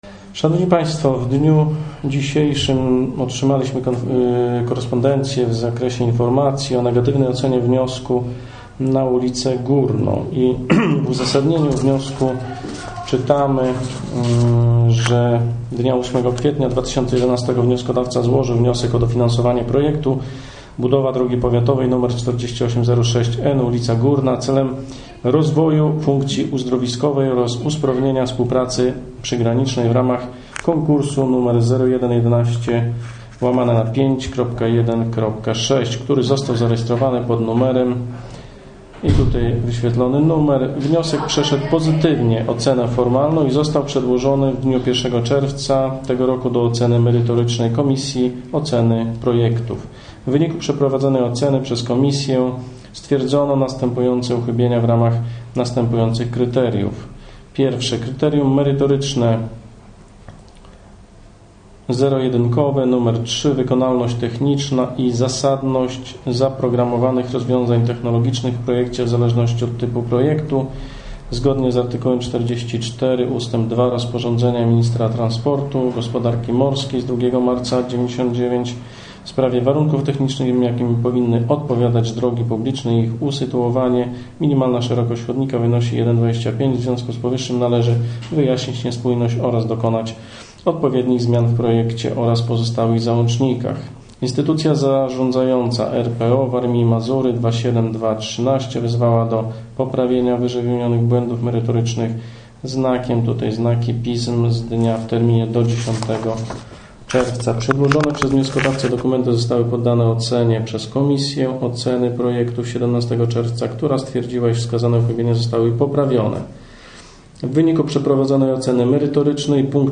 wypowiedź starosty Andrzeja Ciołka